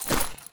Item Purchase (1).wav